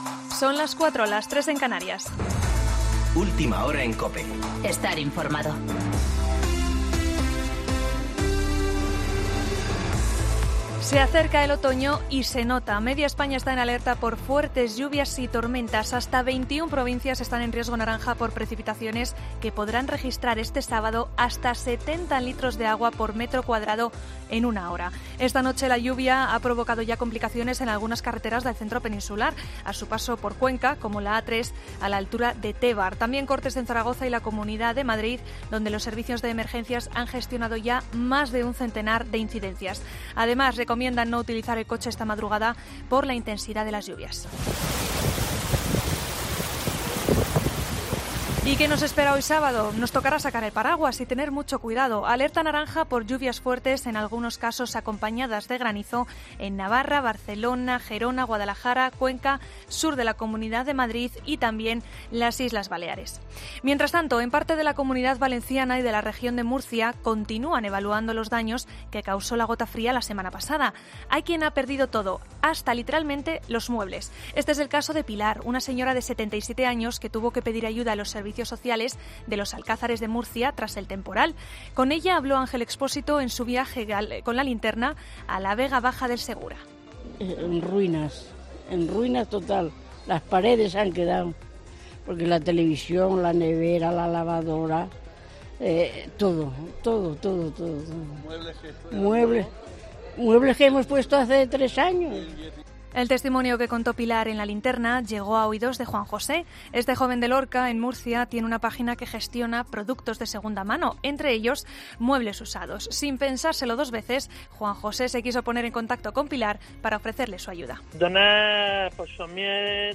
Boletín de noticias COPE del 21 de septiembre del 2019 a las 04:00 horas